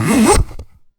inventory_close.wav